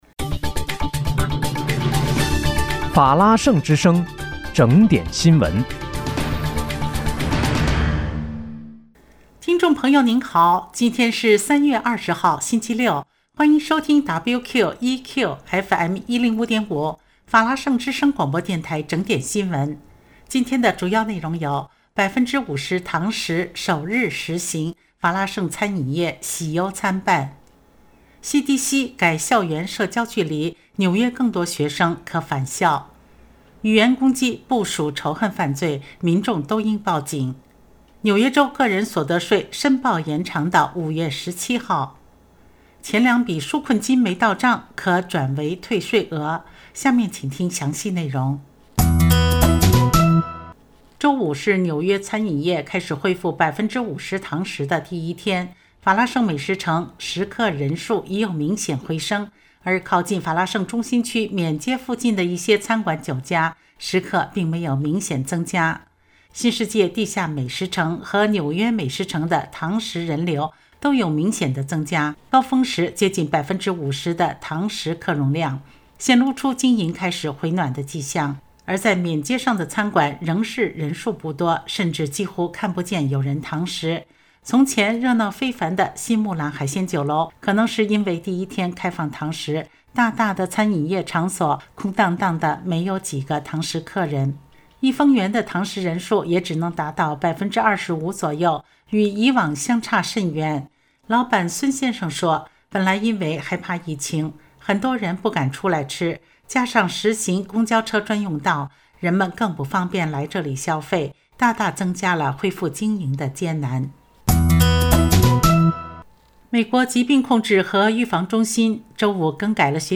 3月20日（星期六）纽约整点新闻